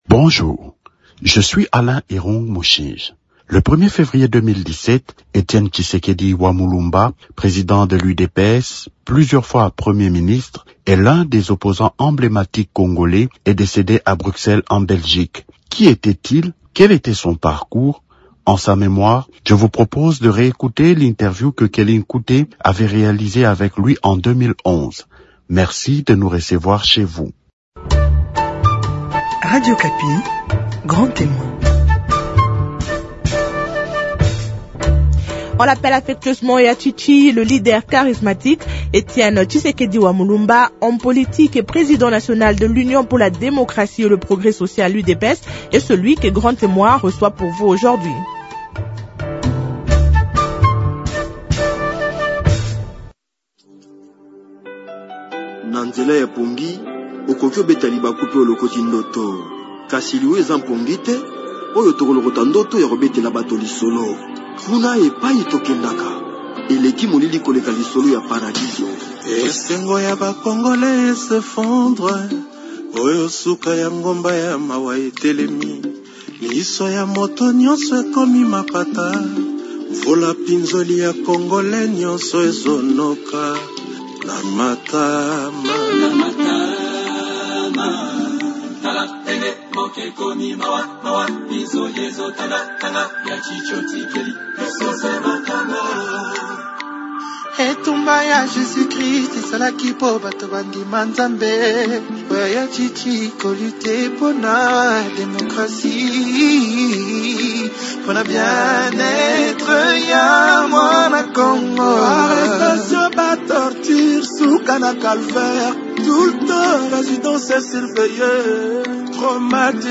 On l’écouter expliquer son parcours ains que la création de l’UDPS, c’était en 2017, au cours de l’émission Grand Témoin